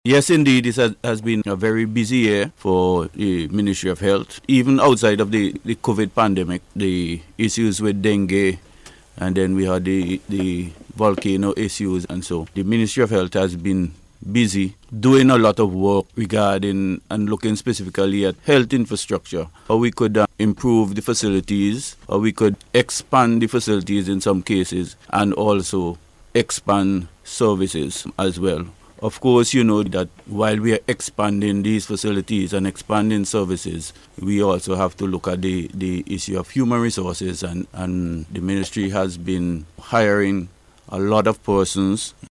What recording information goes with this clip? Speaking on NBC’s Face to Face program this morning